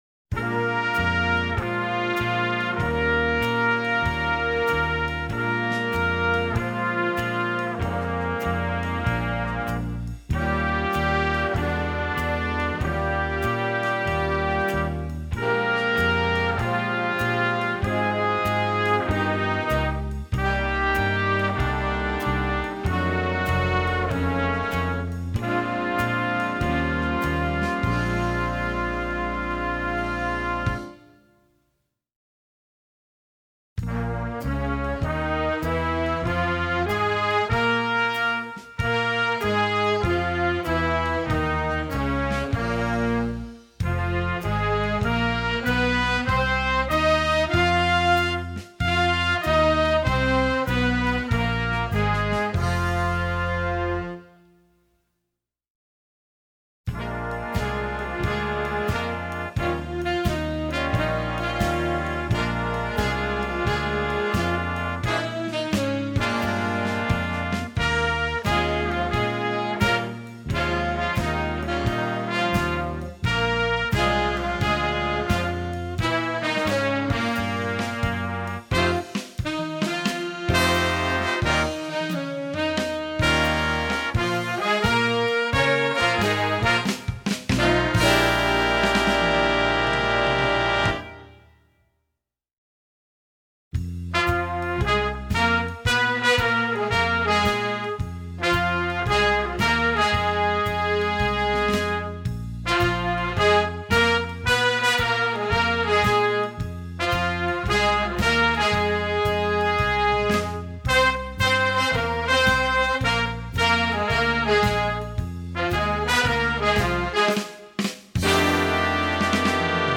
Répertoire pour Jazz band - Jazz Ensemble